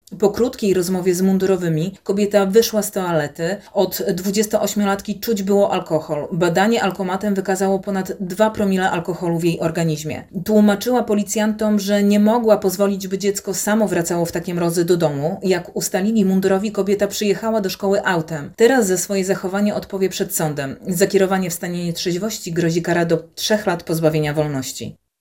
Za kierowanie w stanie jej trzeźwości grozi kara do trzech lat pozbawienia wolności – wyjaśniła policjantka.